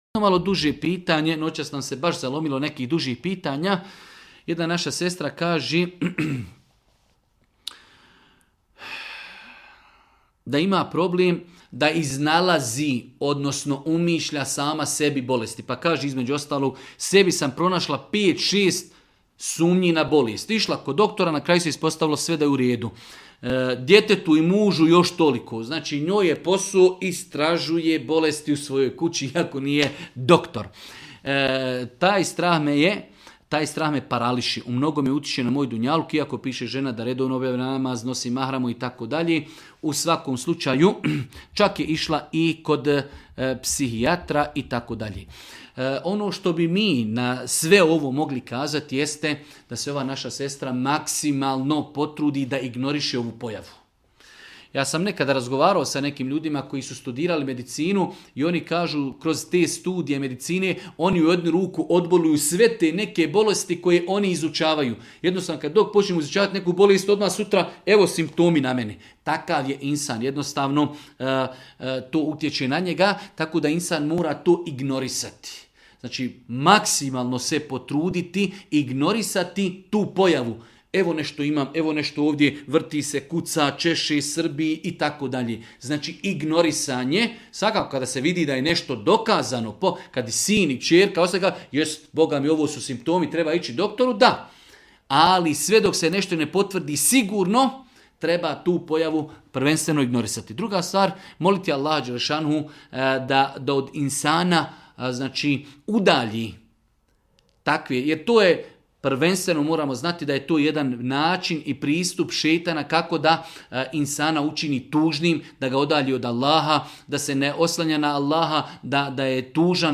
u video predavanju